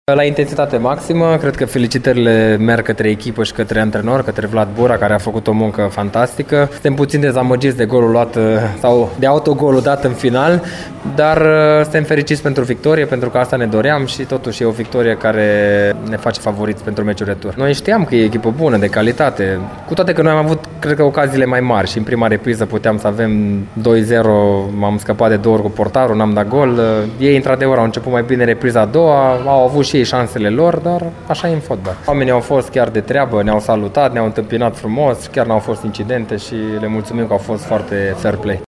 Fostul internațional Paul Codrea este director tehnic la ACS Ghiroda și ne spune cum a trăit meciul de la Lonea.